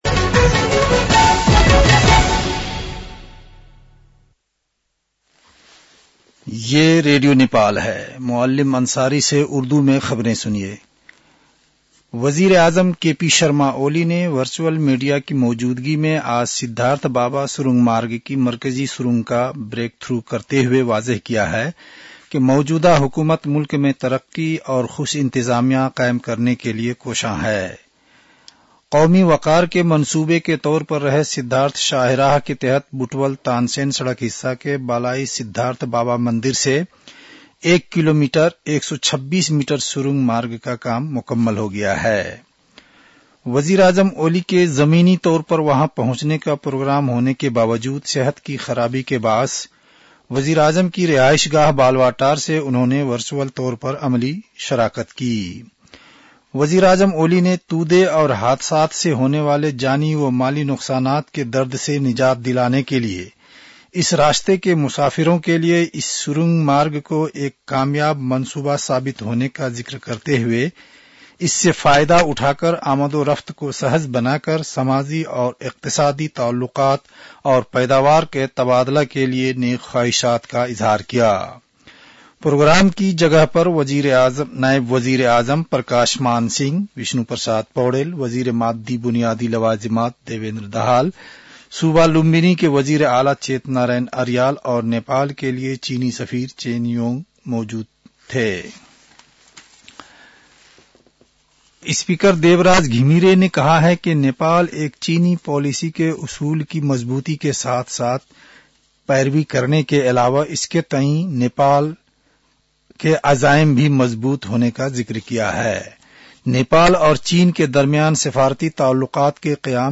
An online outlet of Nepal's national radio broadcaster
उर्दु भाषामा समाचार : १२ माघ , २०८१